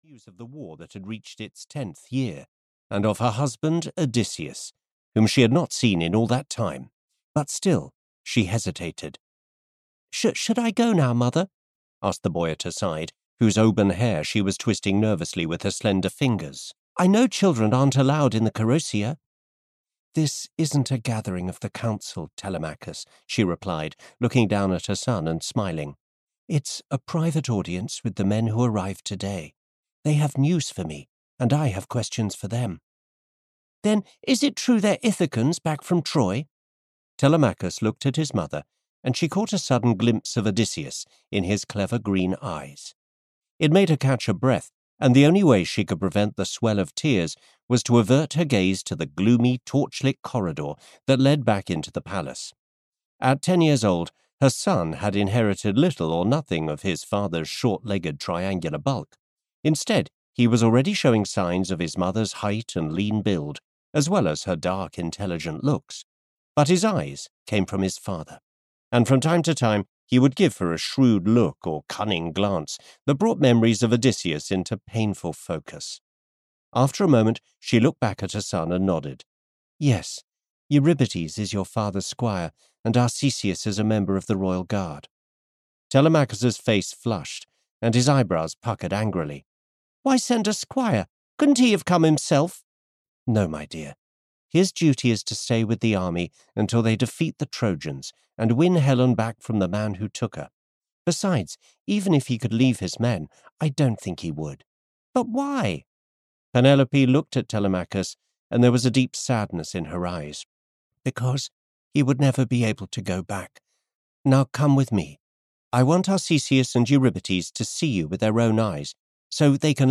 The Armour of Achilles (EN) audiokniha
Ukázka z knihy